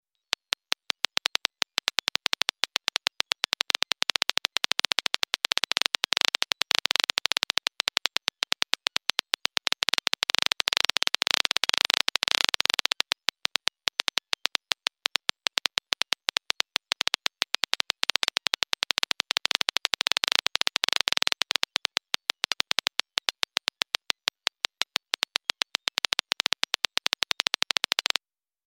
schetchik-geigera_24588.mp3